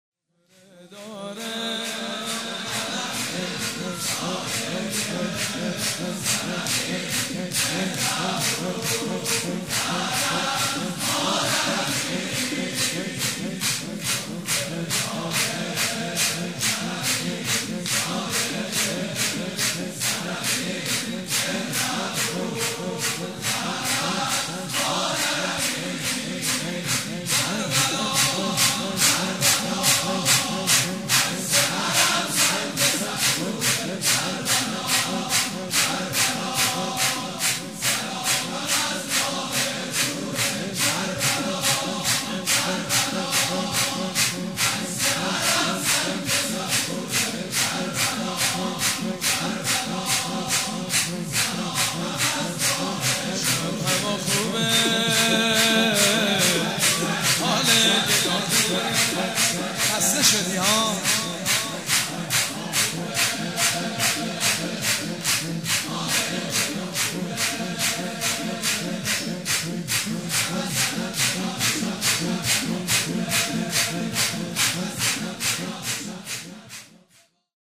سرود: امشب هوا خوبه، حال دلا خوبه